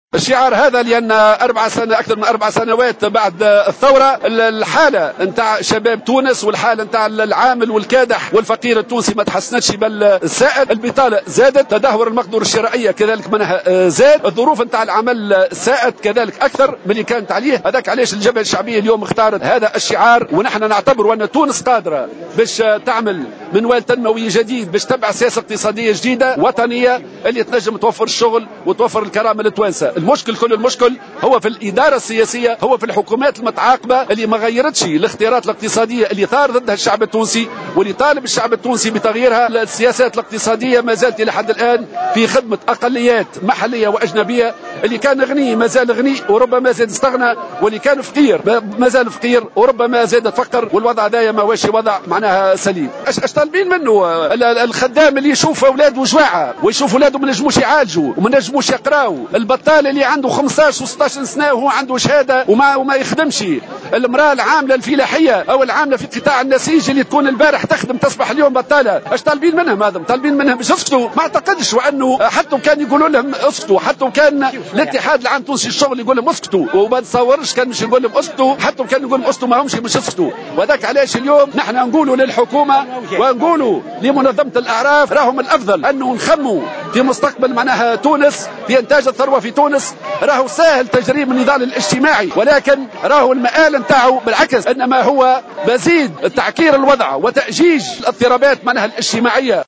أكد الناطق الرسمي بإسم الجبهة الشعبية حمة الهمامي على هامش مسيرة وسط العاصمة احتفالا بعيد الشغل اليوم الجمعة 1 ماي حالة شباب تونس وحالة العامل والكادح و الفقير التونسي من سئ إلى أسوء بعد مرور 4 سنوات على الثورة.